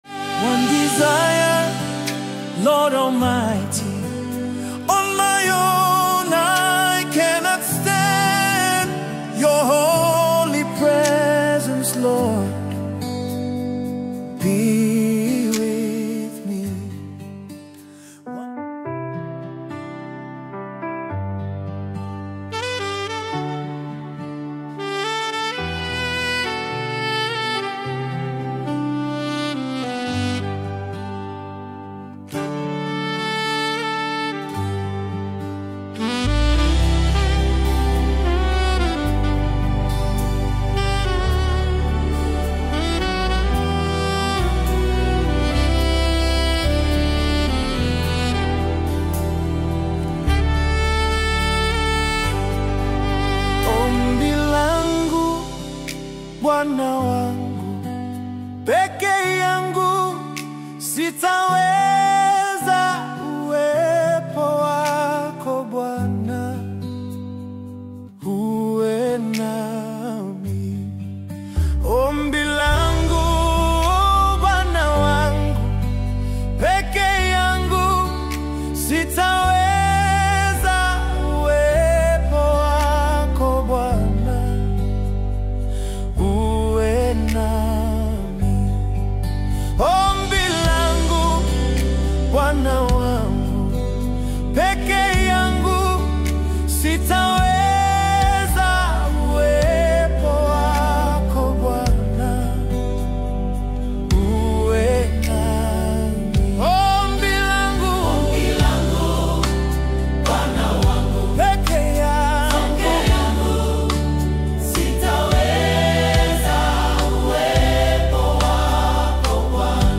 deeply spiritual gospel song